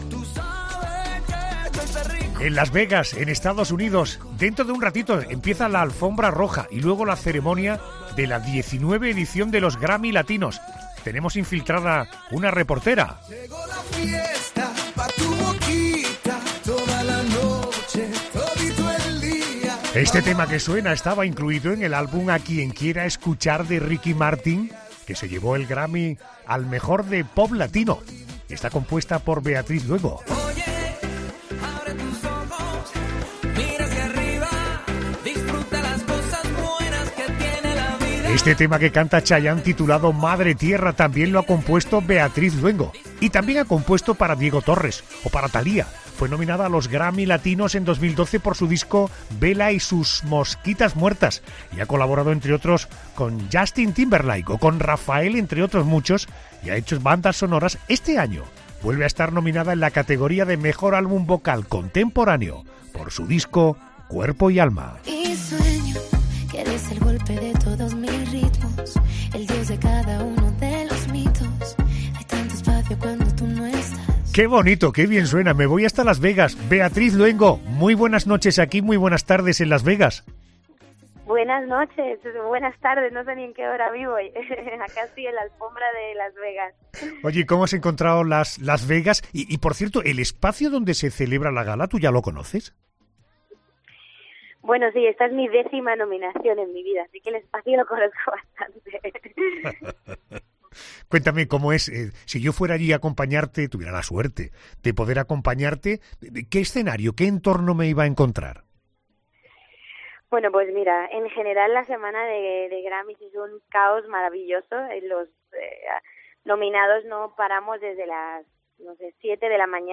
Beatriz Luego aceptó el reto de hacer de reportera infiltrada para La Noche de COPE antes de que se celebrara la gala de los Premios Grammy Latinos